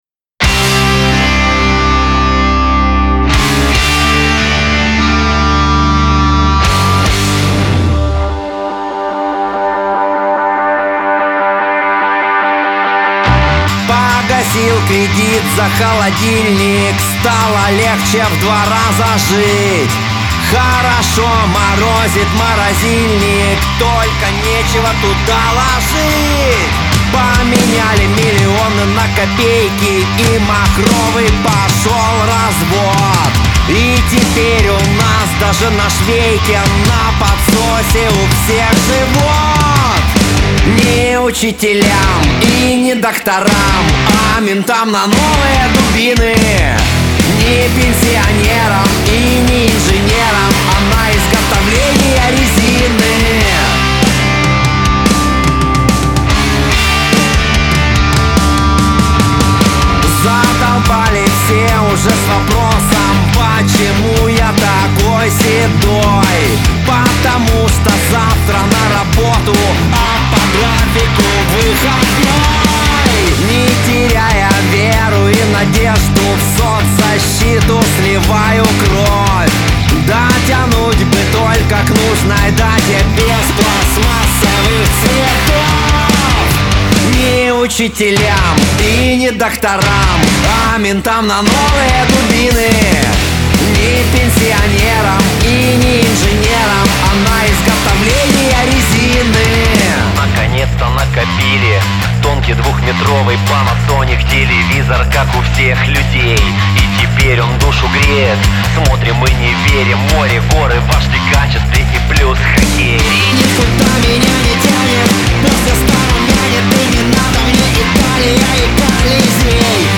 Панк-рок гурт